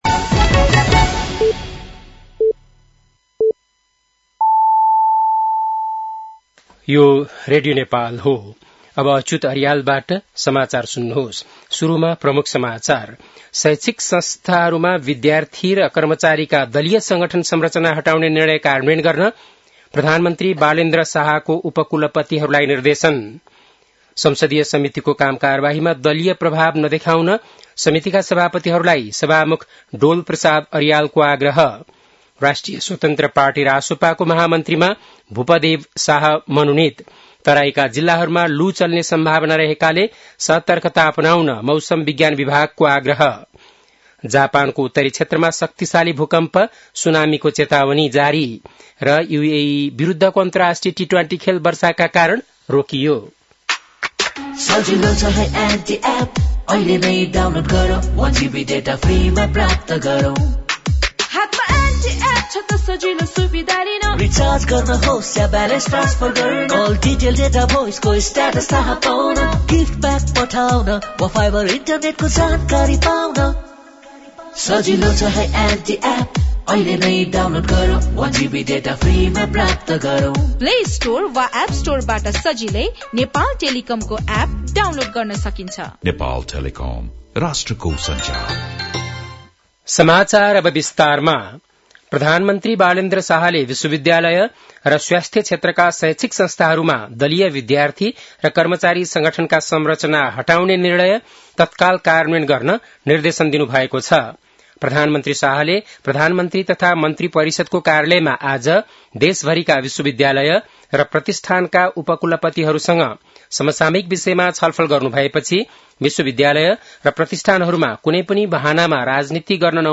बेलुकी ७ बजेको नेपाली समाचार : ७ वैशाख , २०८३
7-pm-nepali-news-1-07.mp3